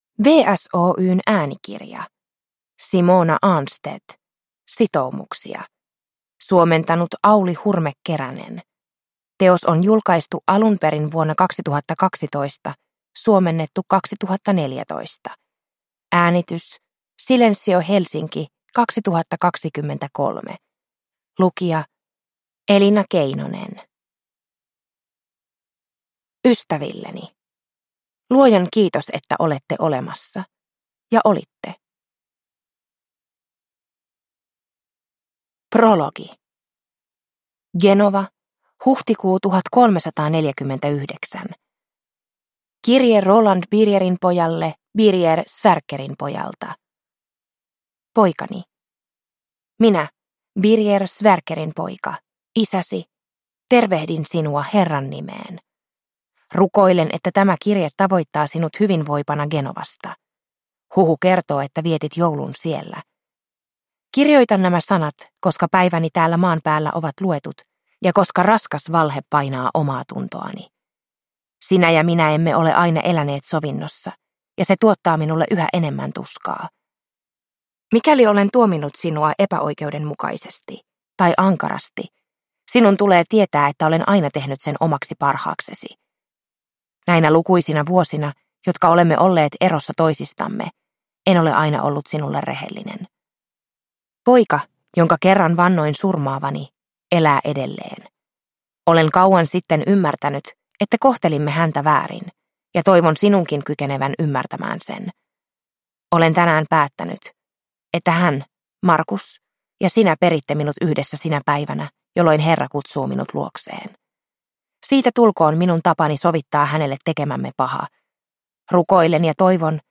Sitoumuksia – Ljudbok – Laddas ner